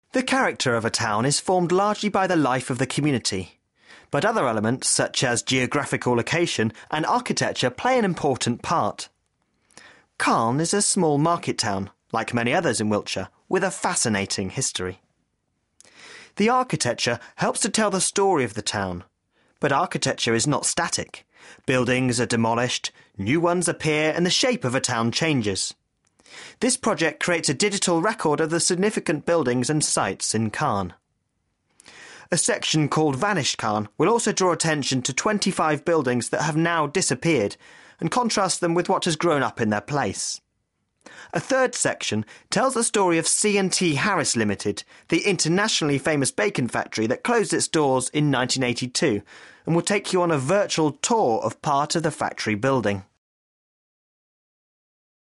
Voice over artist offering online native British accent.